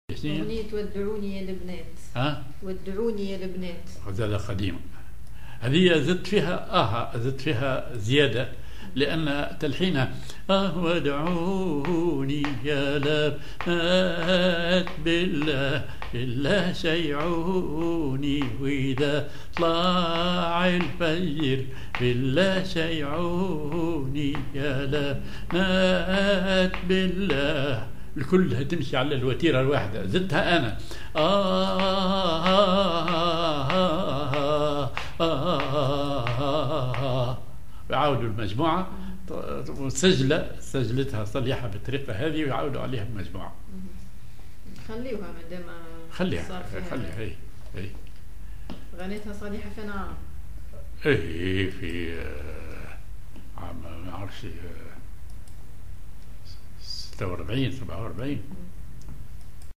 Maqam ar محير سيكاه